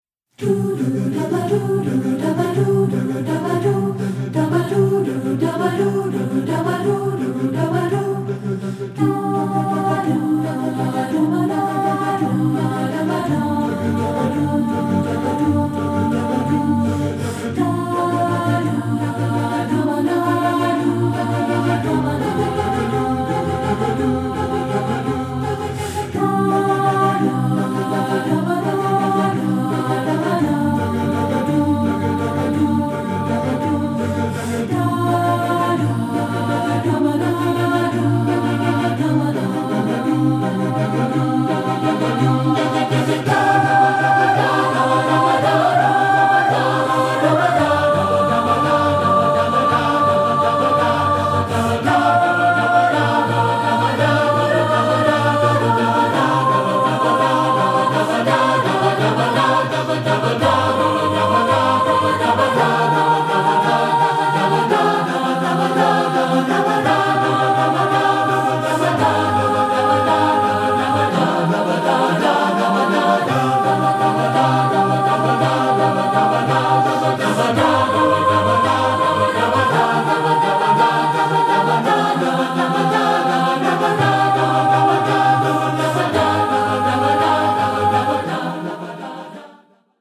Choral Movie/TV/Broadway
SATB A Cap